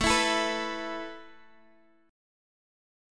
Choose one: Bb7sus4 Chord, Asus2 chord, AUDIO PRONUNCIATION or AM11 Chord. Asus2 chord